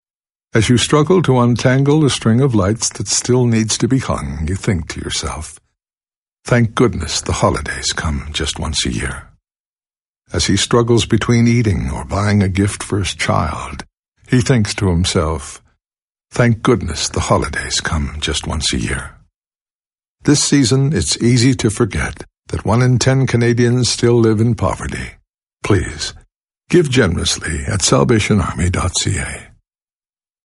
SilverPublic Service - Radio Campaign